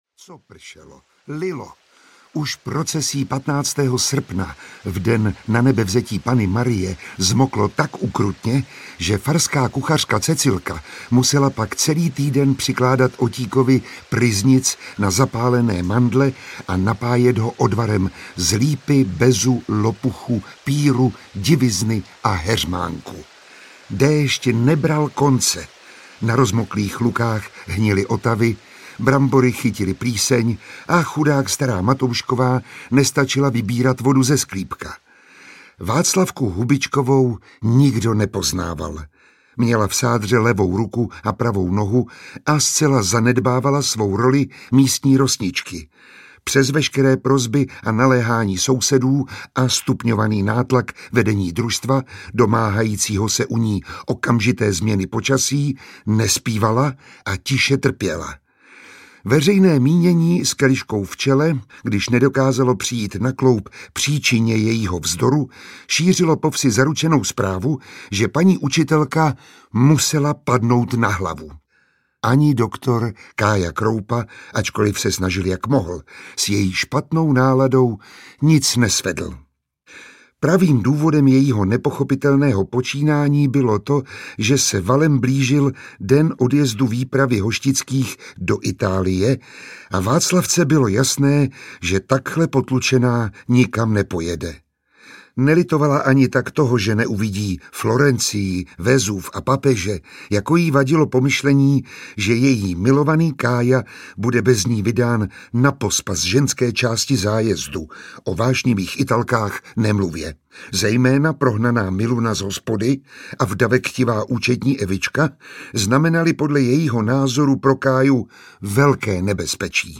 Čte Miroslav Táborský, režie Zdeněk Troška. Svérázní občané jihočeské vesničky Hoštice se vypraví do Itálie.
Ukázka z knihy
Režisér Zdeněk Troška v nahrávacím studiu připravil „film pro uši“. Četbu herce Miroslava Táborského doplňuje řada zvukových efektů a známých hudebních motivů, takže posluchač si celý příběh skvěle vychutná.